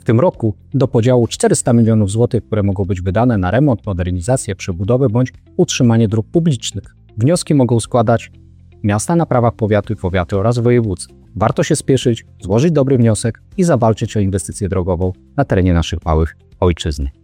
Wojewoda podlaski Jacek Brzozowski.